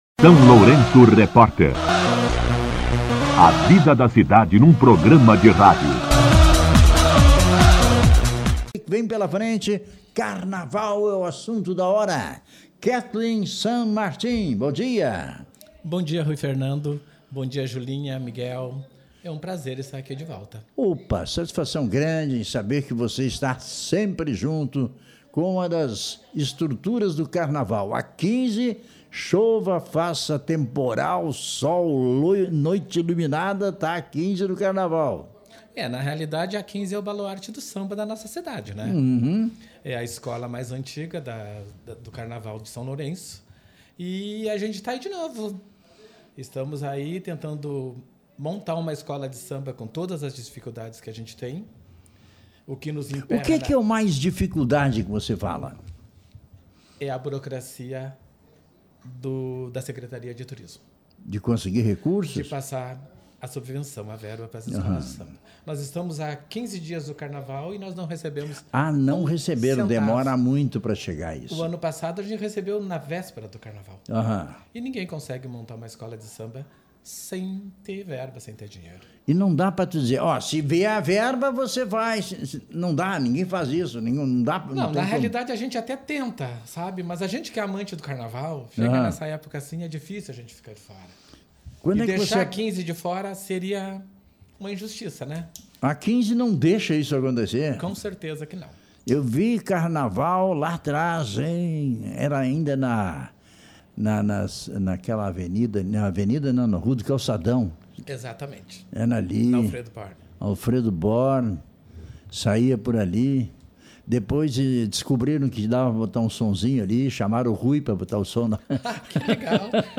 Entrevista com a Carnavalesca
entrevista-carnaval.mp3.mp3